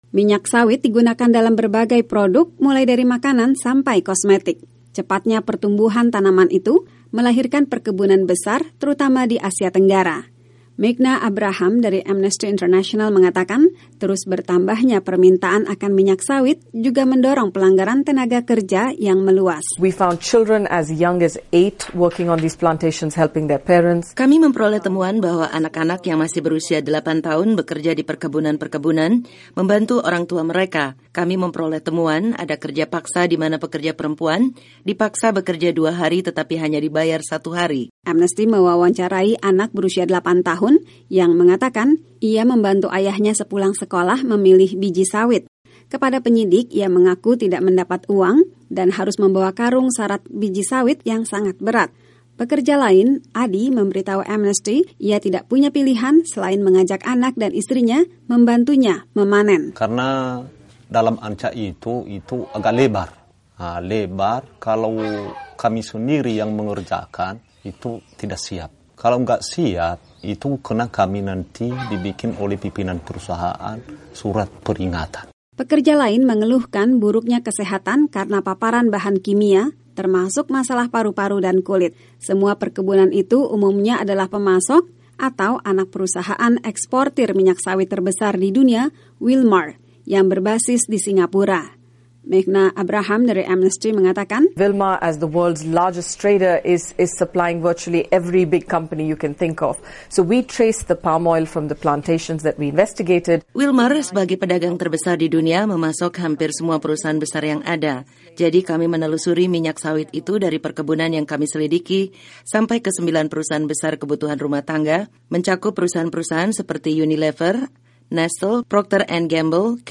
Organisasi hak asasi menuduh produsen minyak sawit di Indonesia melanggar peraturan ketenagakerjaan, menggunakan pekerja anak dan memaksa perempuan bekerja tanpa upah. Laporan wartawan VOA